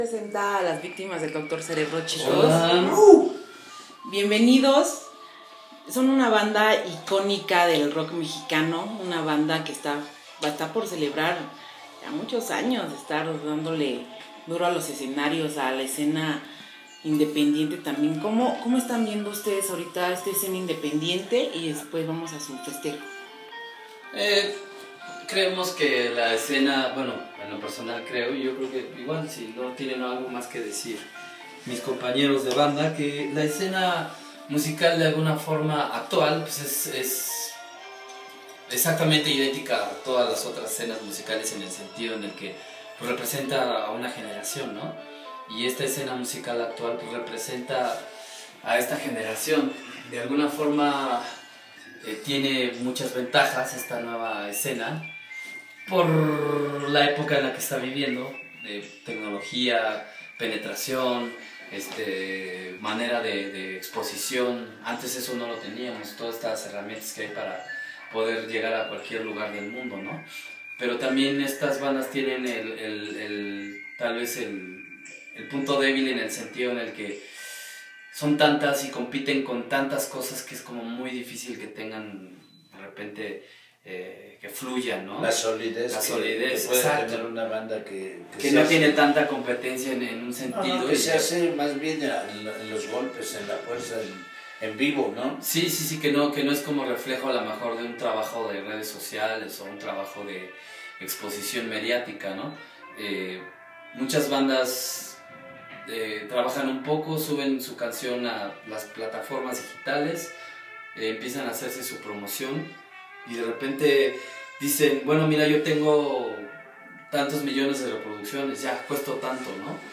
Platicamos con ellos sobre llegar casi a los 30 años, lo que están preparando para este mega pachangon, como ha ido cambiando la escena poco a poco, el box-set edición especial con discos que ya no están editados que sacarán pronto a la venta y porque siguen siendo tan chingones.